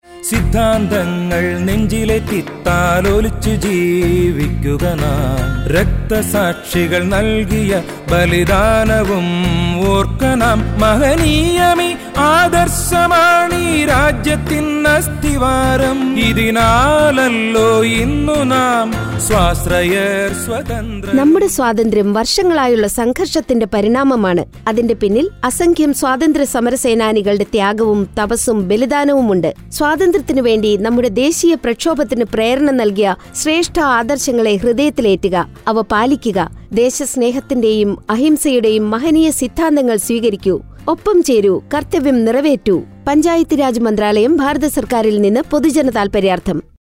125 Fundamental Duty 2nd Fundamental Duty Follow ideals of the freedom struggle Radio Jingle Malayamlam